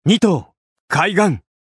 刀剑乱舞_Nankaitarou-doubleattackcallout.mp3